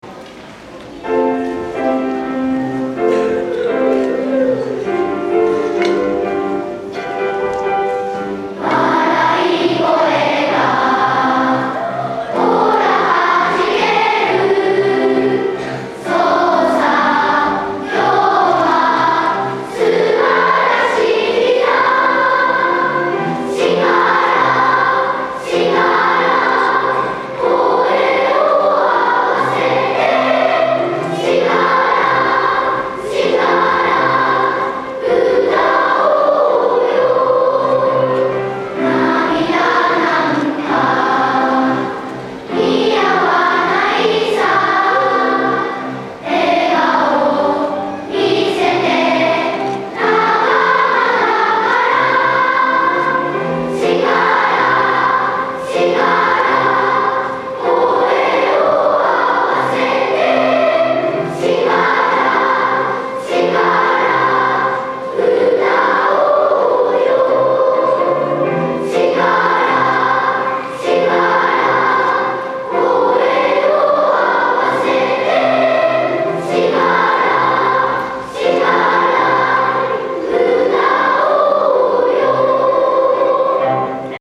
歌うと明るい気持ちになるすてきな曲で